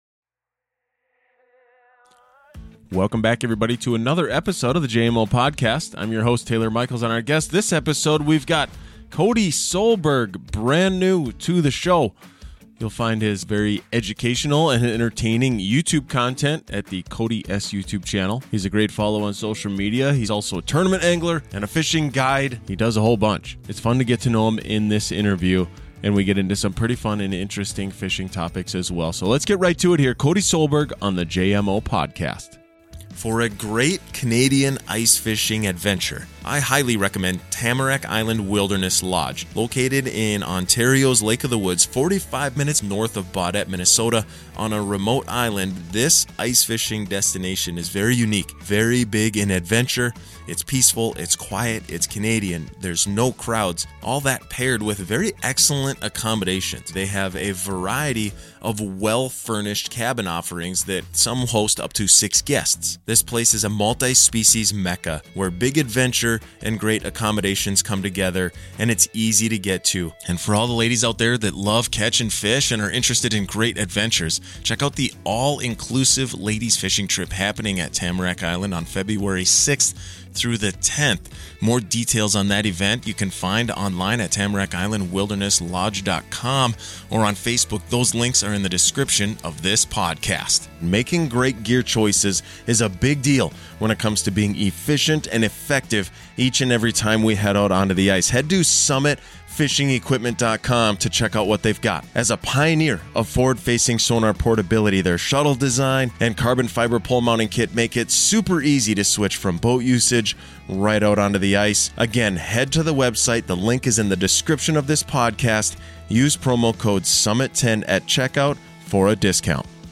In this interview we learn where he discovered his passion for fishing and how he has evolved as an angler as well as an entrepreneur.